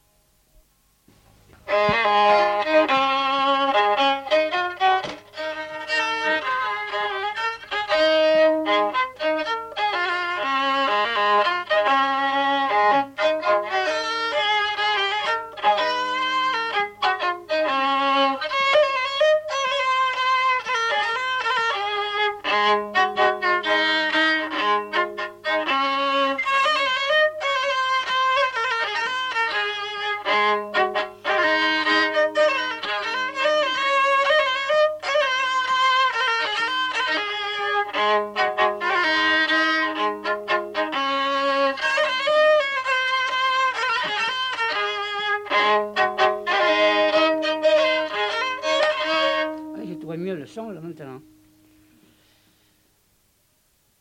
Rondeau